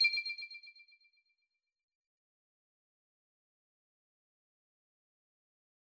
back_style_4_echo_006.wav